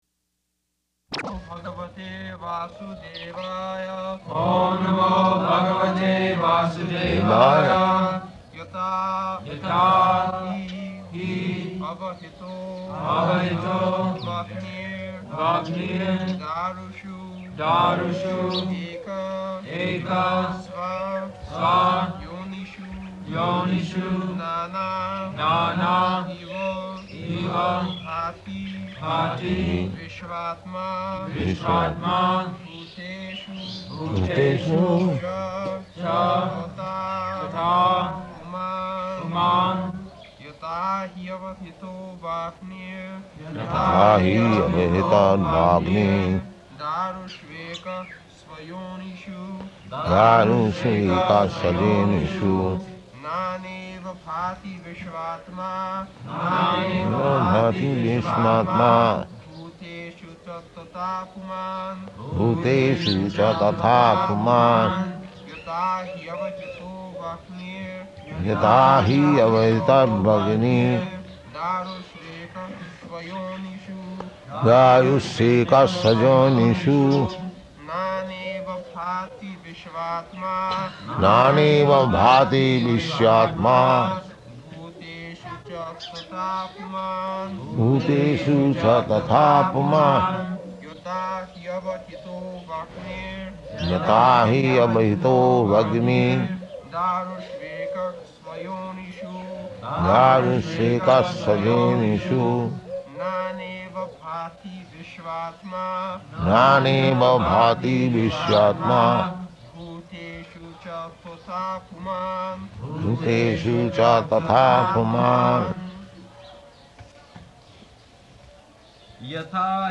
November 11th 1972 Location: Vṛndāvana Audio file
[leads chanting of verse, etc.] [Prabhupāda and devotees repeat]